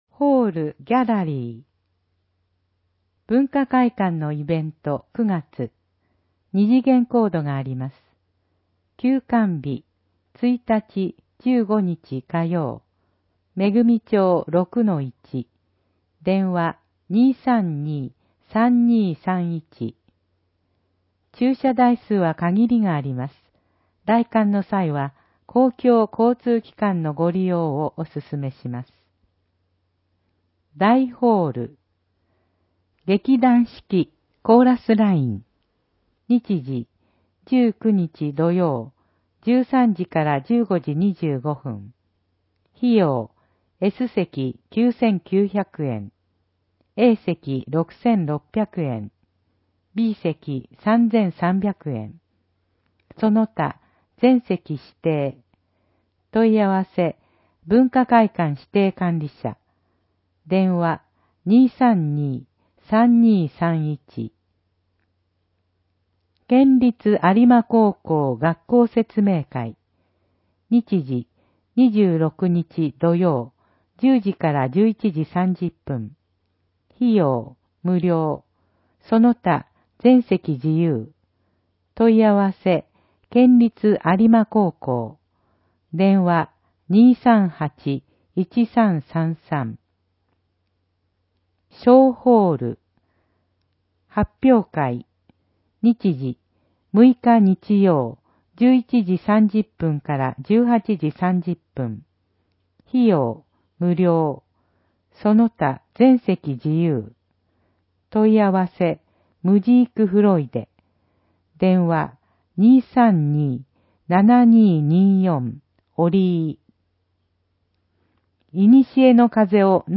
広報えびな 令和2年8月15日号（電子ブック） （外部リンク） PDF・音声版 ※音声版は、音声訳ボランティア「矢ぐるまの会」の協力により、同会が視覚障がい者の方のために作成したものを登載しています。